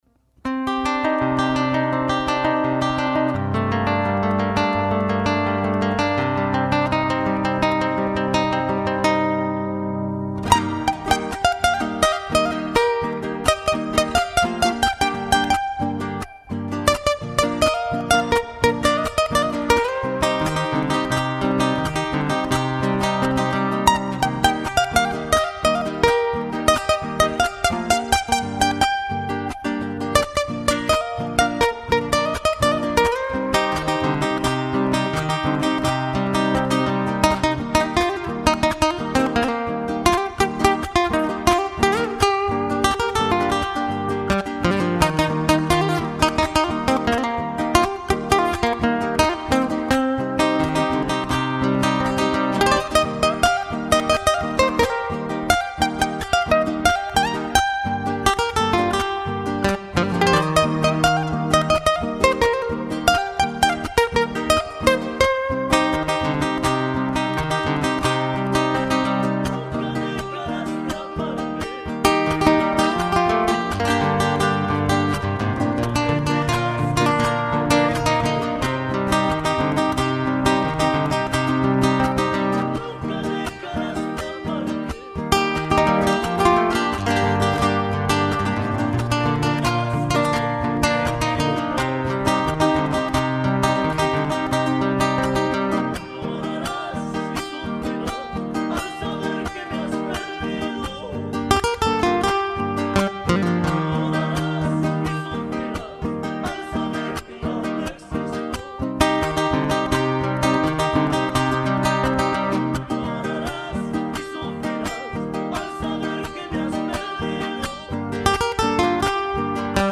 Tono: Cm Capo: III traste